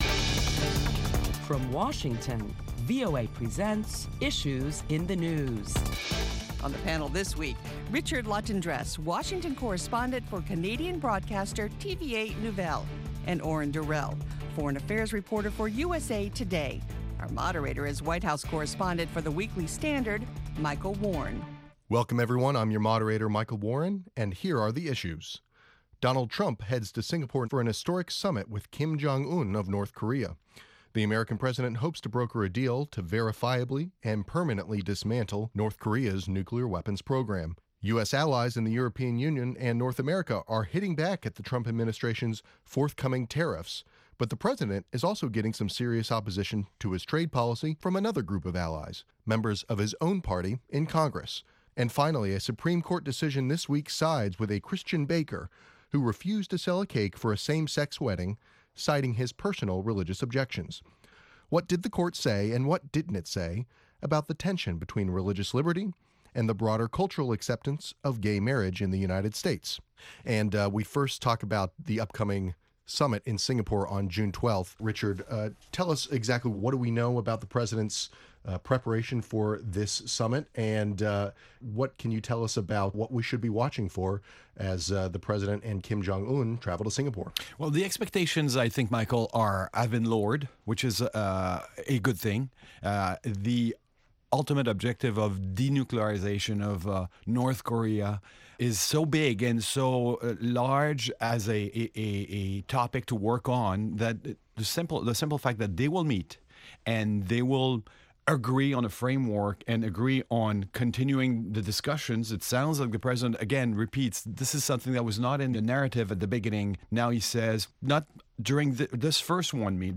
round table discussion